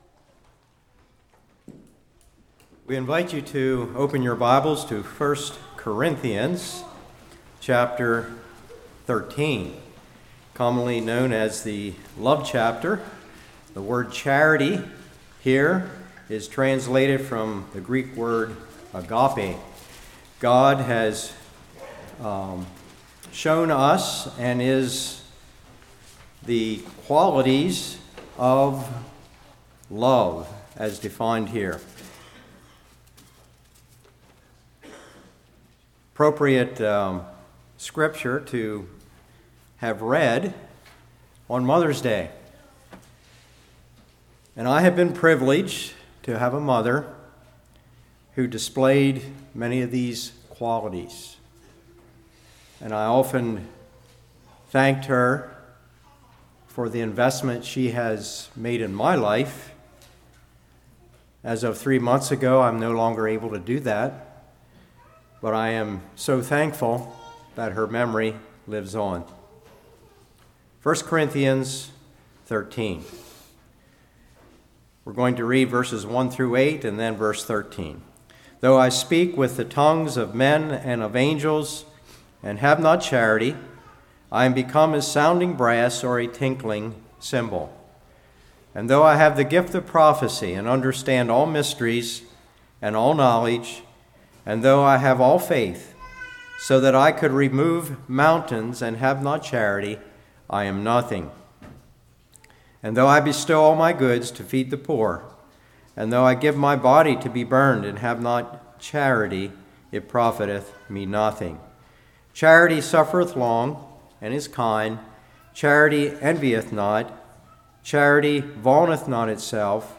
13 Service Type: Morning What Is a Mother’s Love Worth?